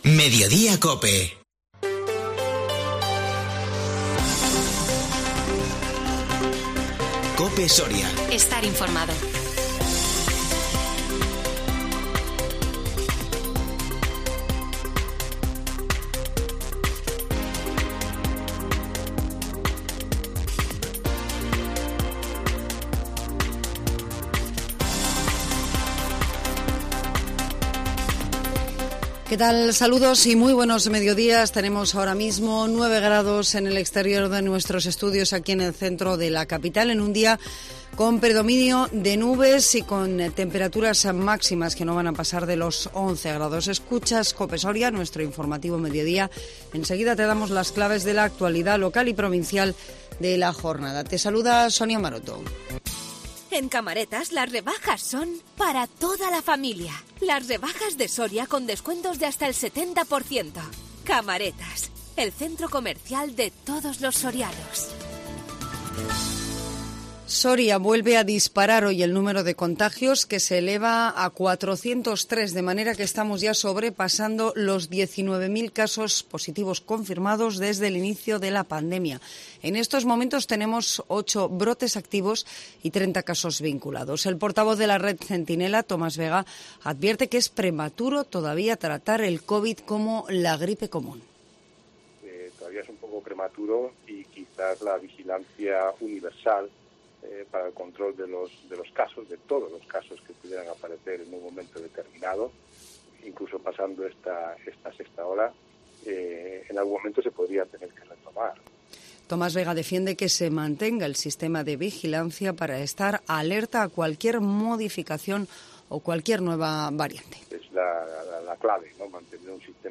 INFORMATIVO MEDIODÍA 11 ENERO 2021